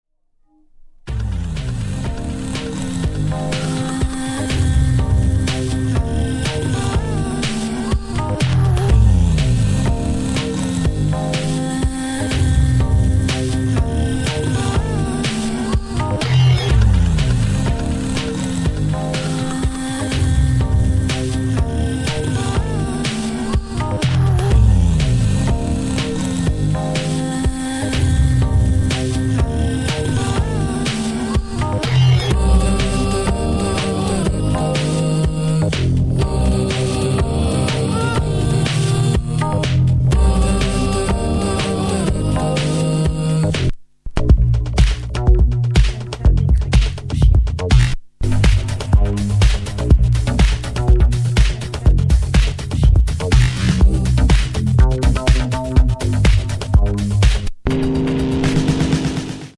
remixed and made danceble and mixable